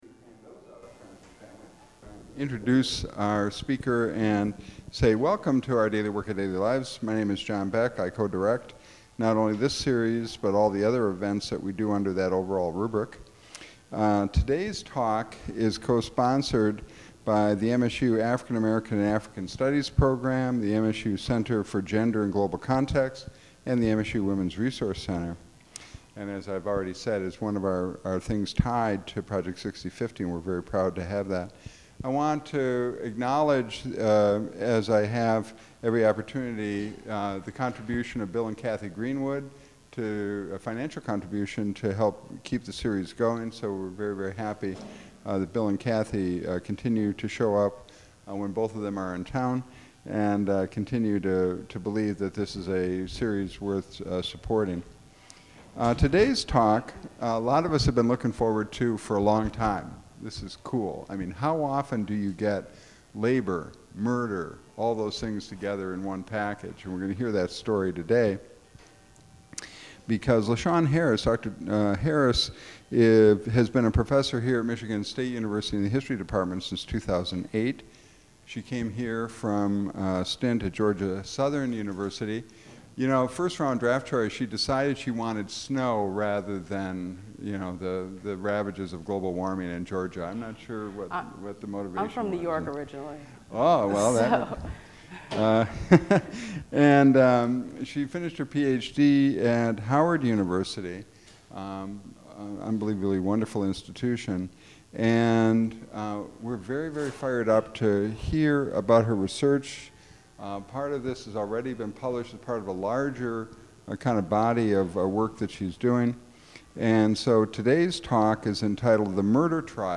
A question and answer session follows.
Held at the MSU Museum.